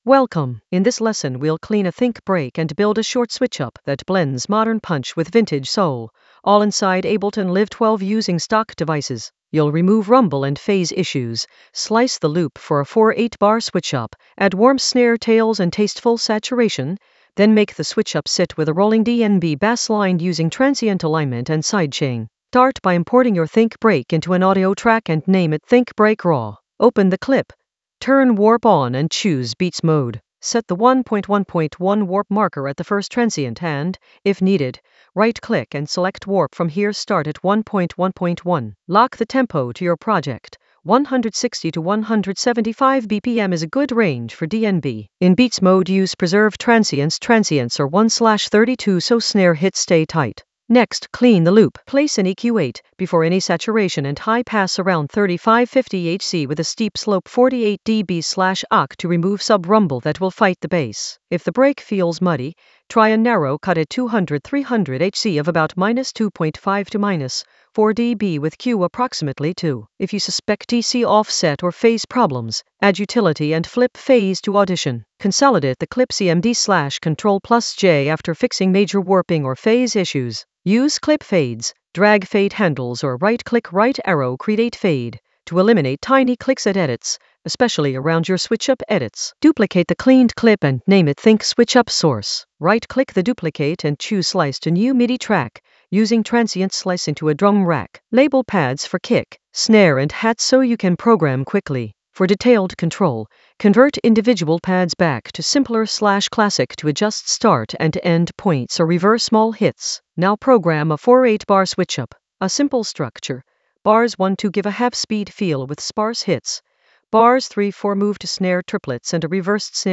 An AI-generated intermediate Ableton lesson focused on Clean a think-break switchup for modern punch and vintage soul in Ableton Live 12 in the Basslines area of drum and bass production.
Narrated lesson audio
The voice track includes the tutorial plus extra teacher commentary.